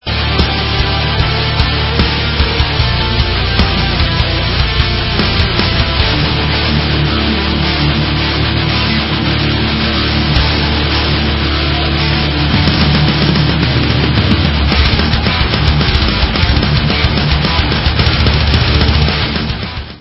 sledovat novinky v oddělení Rock - Speed/Thrash/Death Metal